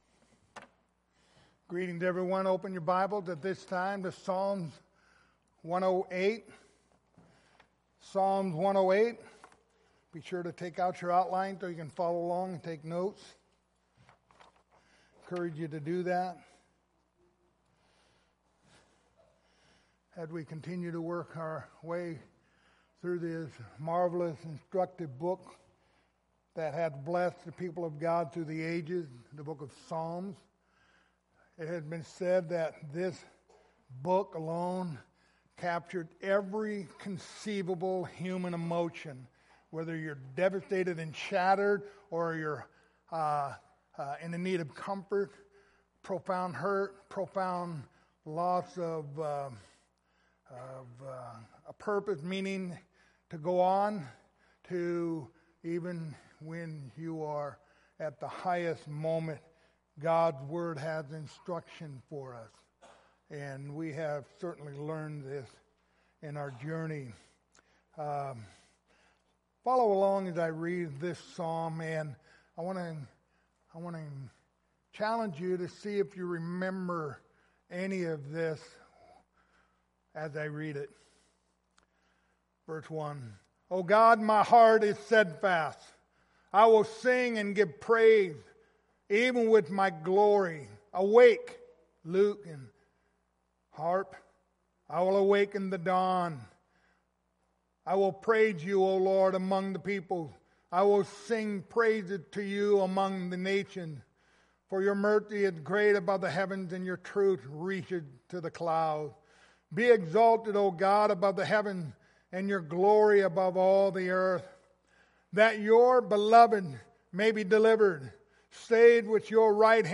The book of Psalms Passage: Psalms 108:1-13 Service Type: Sunday Morning Topics